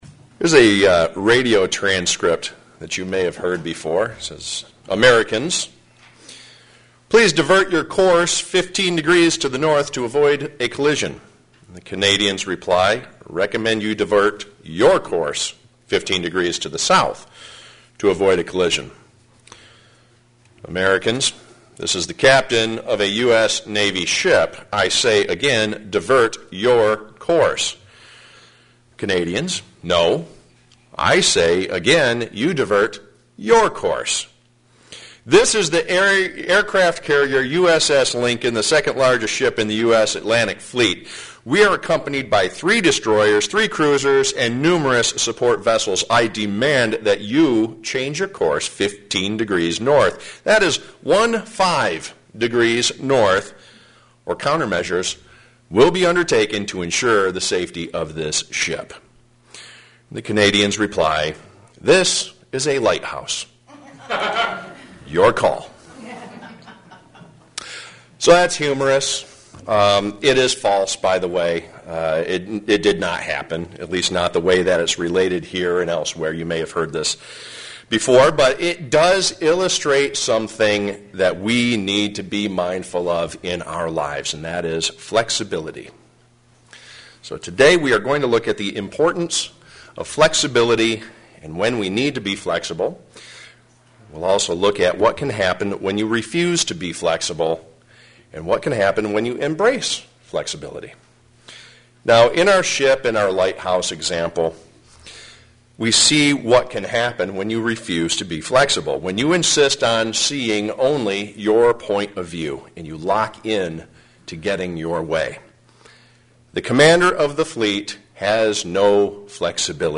Given in Flint, MI
When ought we not to be flexible? sermon Studying the bible?